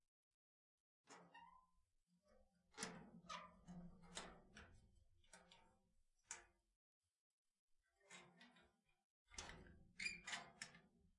Squeaks » Gate Squeak 03
描述：Gate Squeaking, Variation 3 of 3. Unprocessed 44.1KHz, 16bit, Wav.
标签： close door gate house metal open squeak
声道立体声